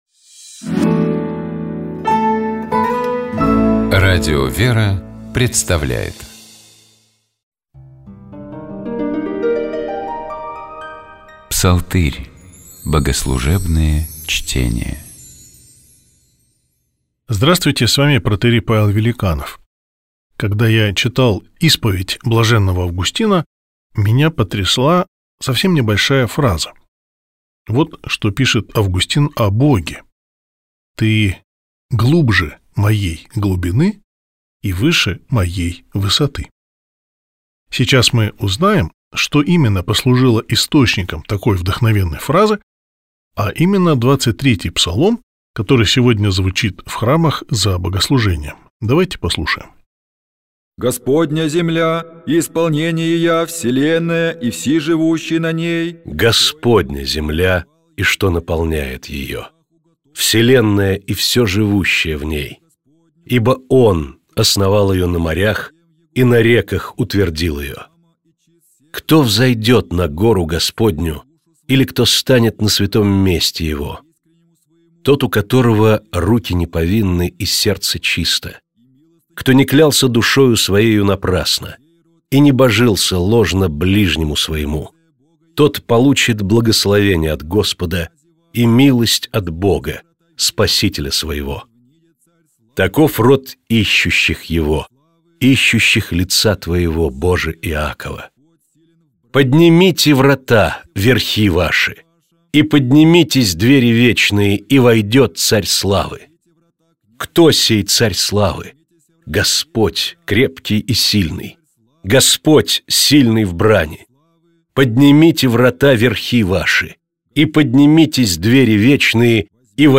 Завершается финал колокольным звоном, который звучит как с оркестром, так и отдельно. А почему бы нам не устроить такой концерт, и наши колокола чтобы звучали, они же этого достойны!